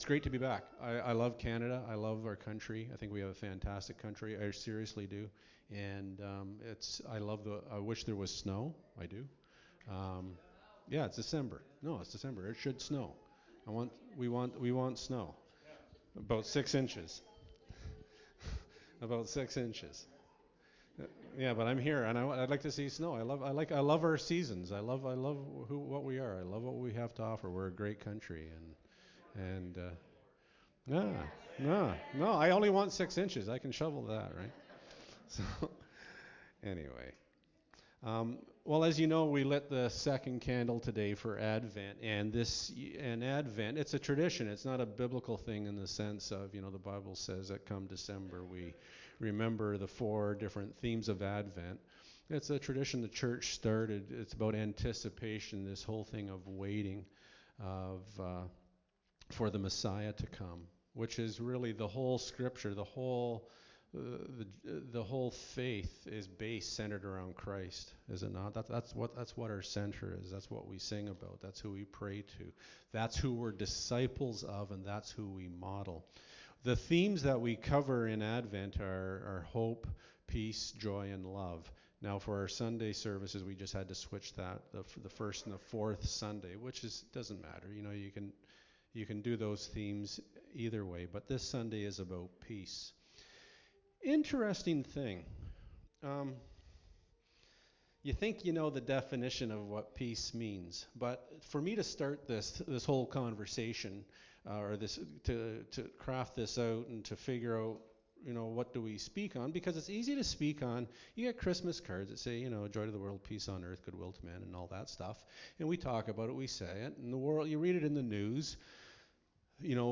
Ephesians 2:14-16 Service Type: Sunday Morning Bible Text